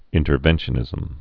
(ĭntər-vĕnshə-nĭzəm)